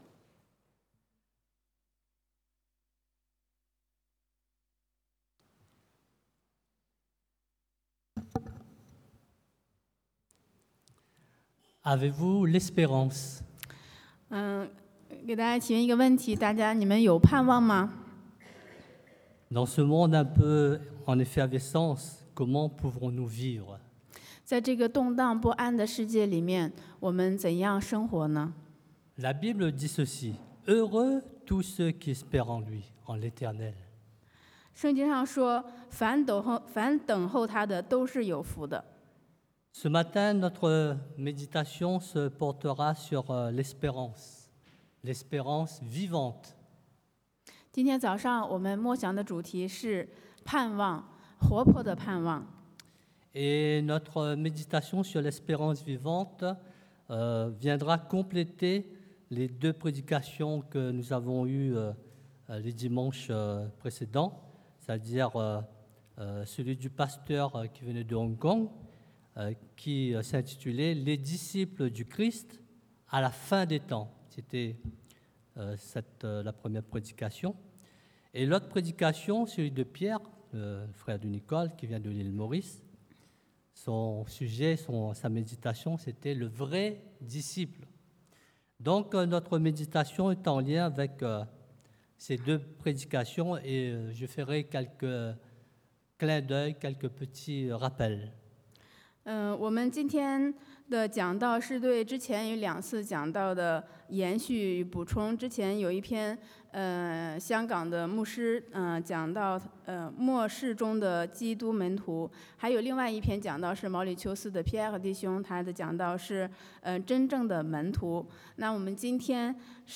Passage: Luc 路加福音 24 : 13-34 Type De Service: Predication du dimanche « Redécouvrir l’adoration à Dieu 重新發現對上帝的敬拜 Me voici